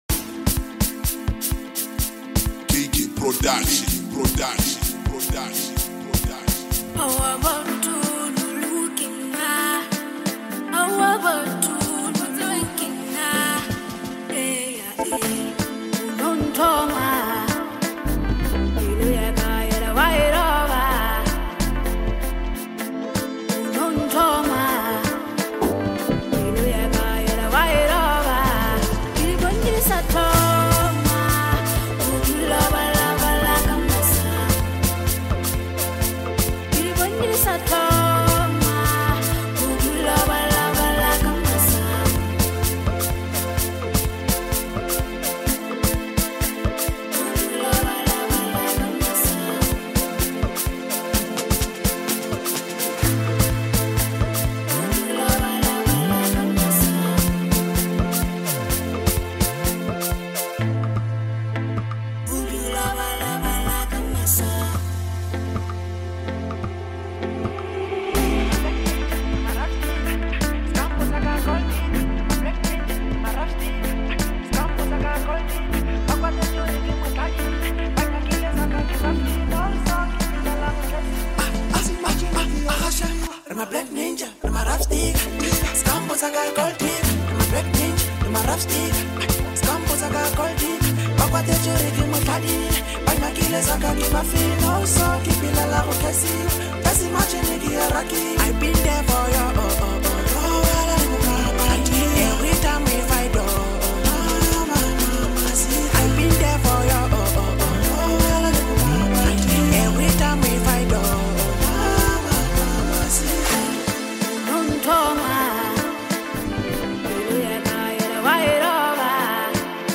heartfelt,soulful masterpiece
smooth vocals and expressive depth